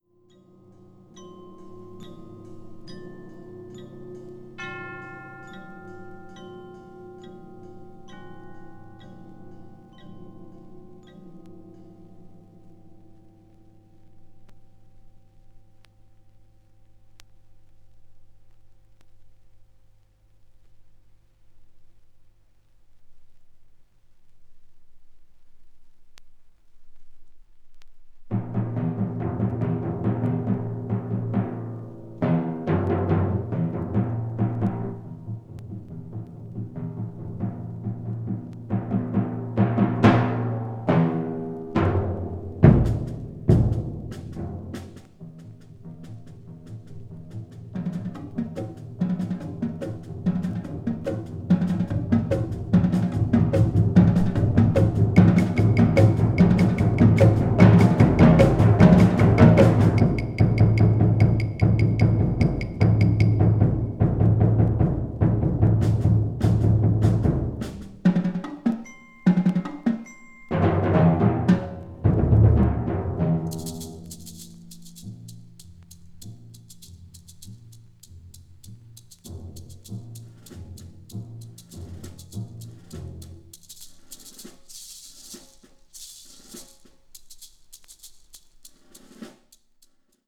media : EX/EX(some slightly noise.)
The sound quality is also exceptional.